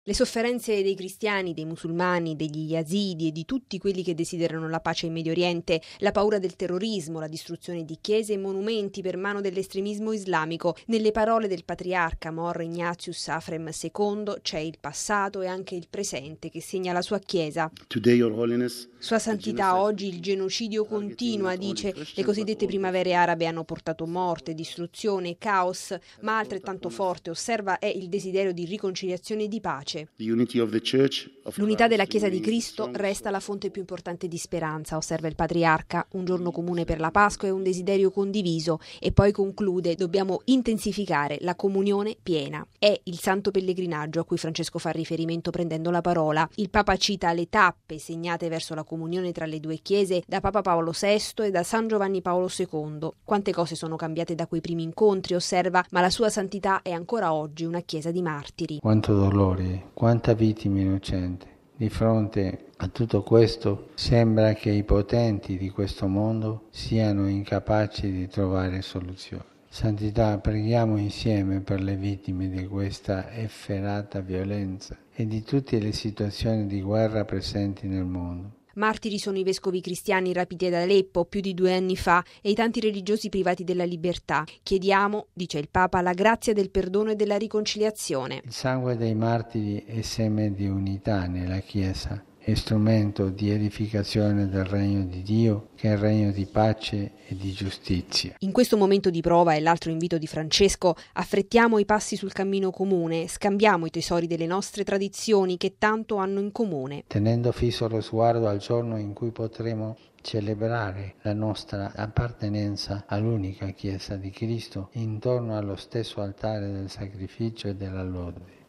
Francesco ha invitato Sua Santità Mor Ignatius Aphrem II a pregare per tutti i martiri del Medio Oriente e ad affrettare il cammino di piena comunione tra le due Chiese. Il servizio